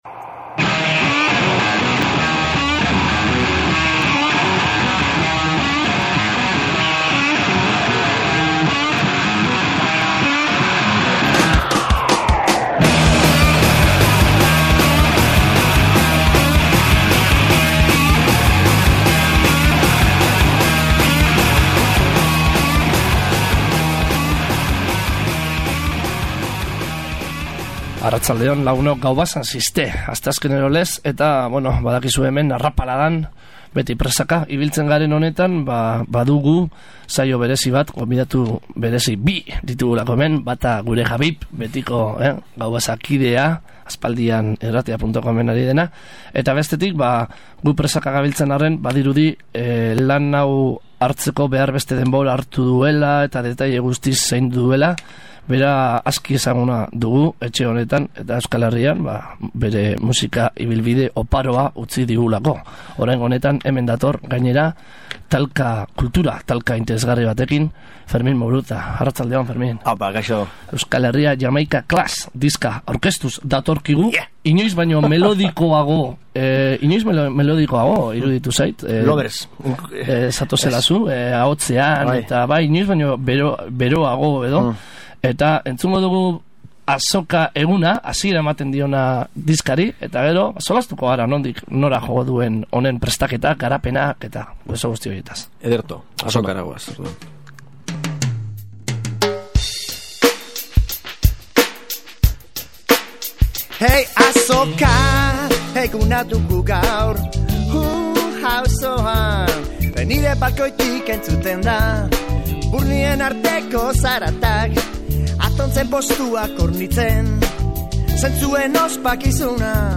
Elkarrizketa luze eta mamitsua egin diogu Fermin Muguruzari Gaubasa saioan, bere azken diskoa dela eta. Jamaikara joan zaigu oraingoan Fermin Euskal Herria Jamaika Clash deritzan diskoa grabatzera.